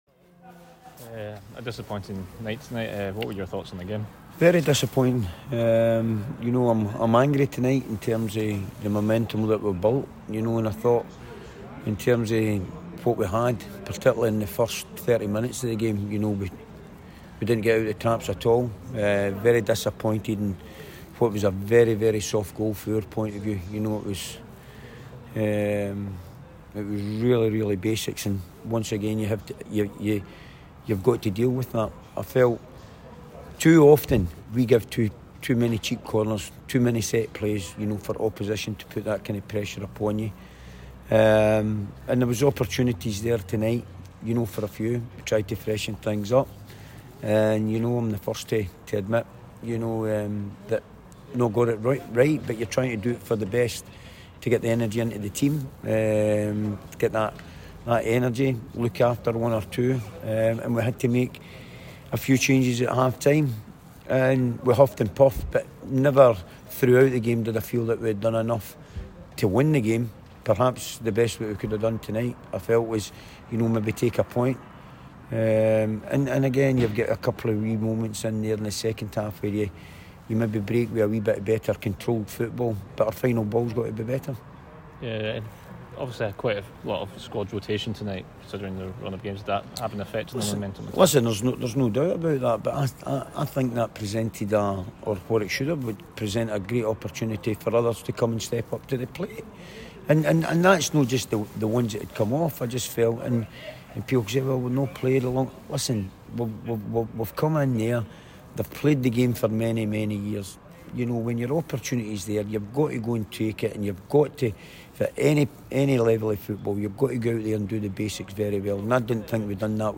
Danny Lennon's press conference after the League 1 match.